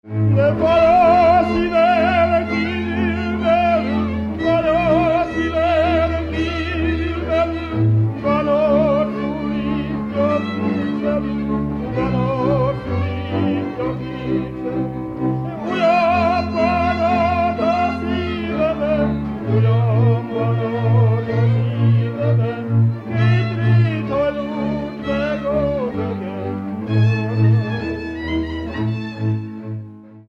Erdély - Kis-Küküllő vm. - Csávás
ének
hegedű
kontra (háromhúros)
bőgő
Műfaj: Lassú csárdás
Stílus: 4. Sirató stílusú dallamok